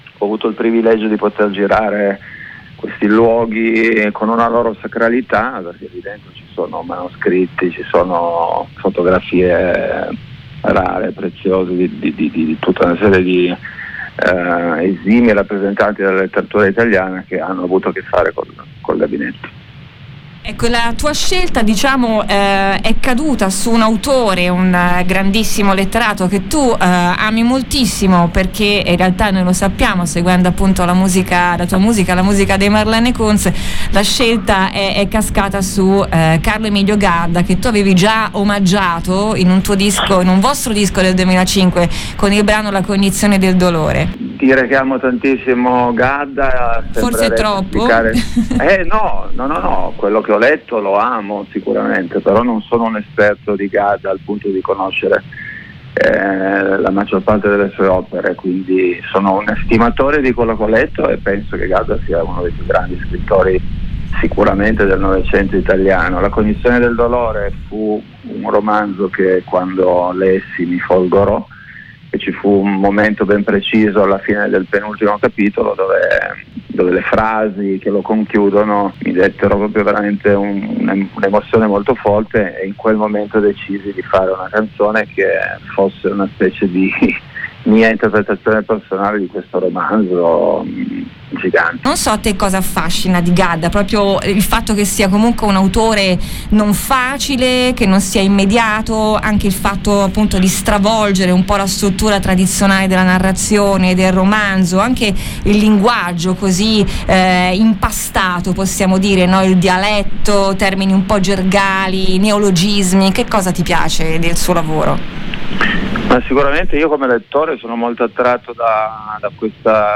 Questo giovedì 4 settembre alle 21: Cristiano Godano (Marlene Kuntz) con parole e musica dal vivo dedicate a Carlo Emilio Gadda. L’intervista